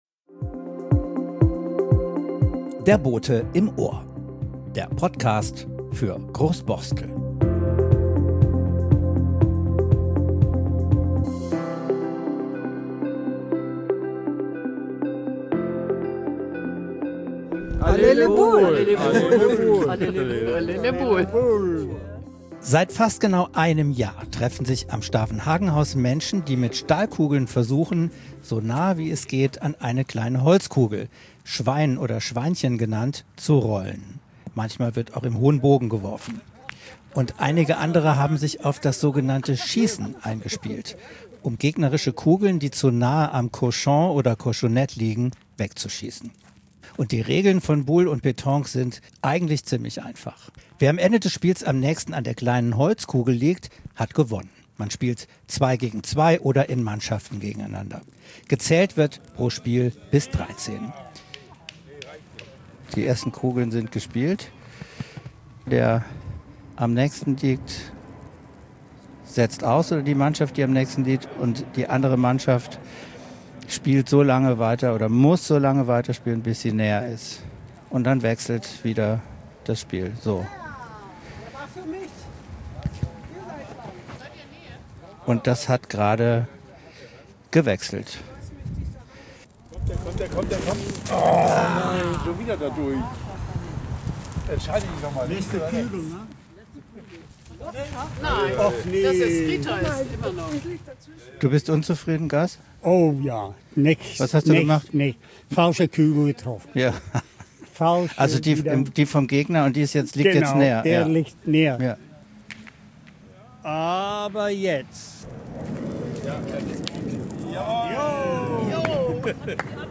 Dieser Podcast nimmt Euch mit auf die Boule-Bahn am Stavenhagenhaus!